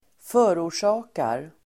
Uttal: [²f'ö:ror_sa:kar]